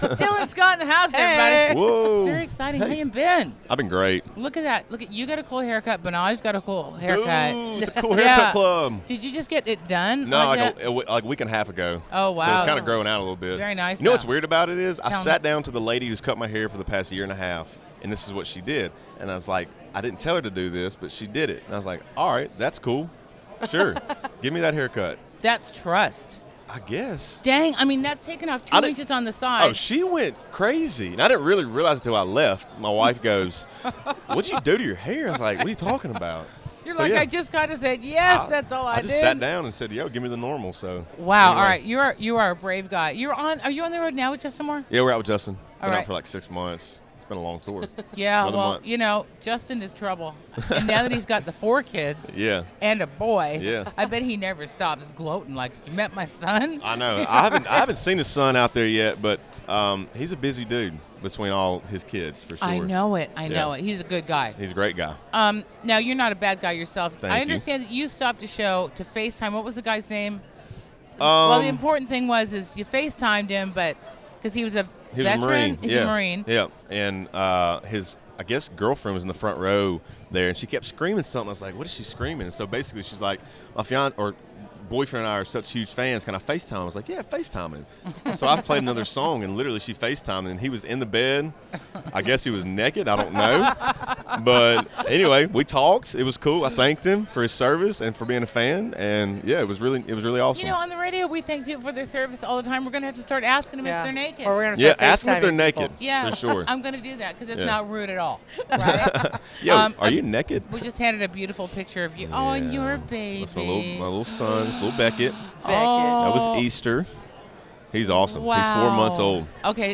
Dylan Scott Interview At The 2018 ACMs!